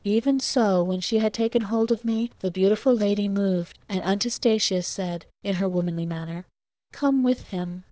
We take 100 test samples from the dev-clean subset of LibriTTS for testing.